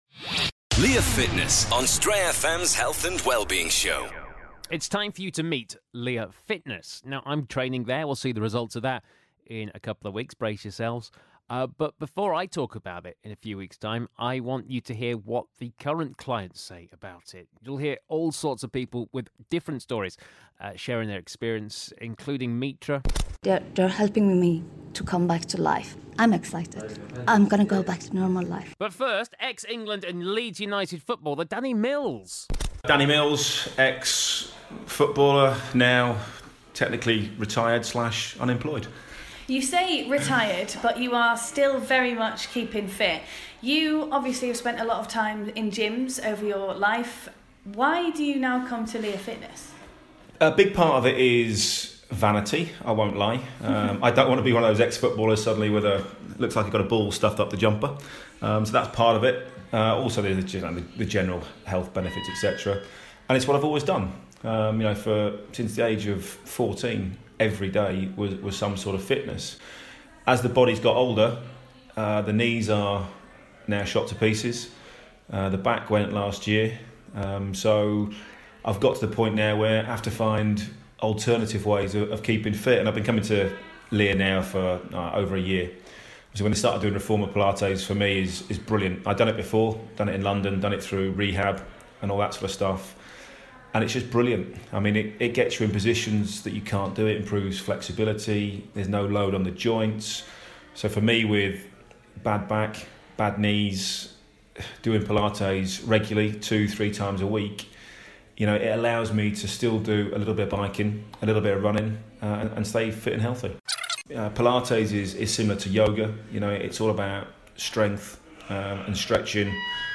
Hear from a selection of people who have had incredible results at Lear Fitness. We hear from footballer Danny Mills, a cancer survivor, and somebody who went through depression: all three owe a lot of their recovery to the team at Lear Fitness.